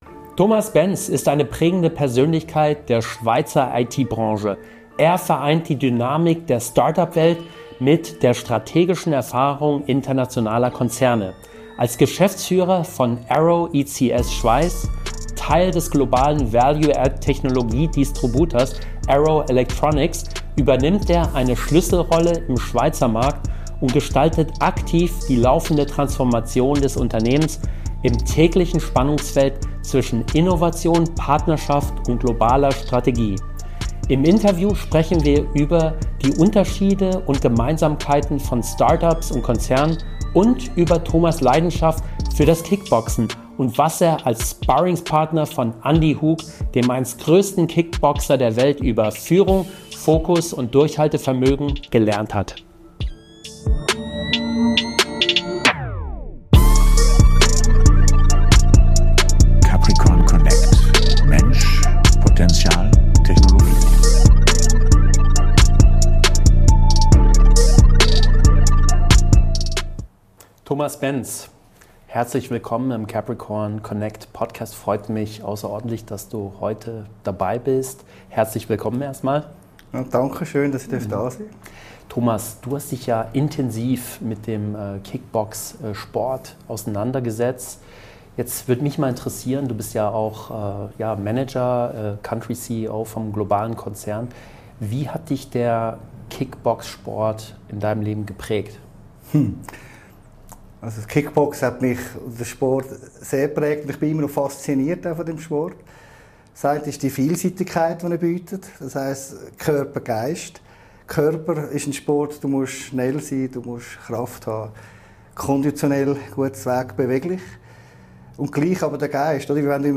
Ein inspirierendes Gespräch über Leadership, Transformation und persönliches Wachstum, mit klaren Learnings für Unternehmer, Führungskräfte und alle, die sich weiterentwickeln wollen.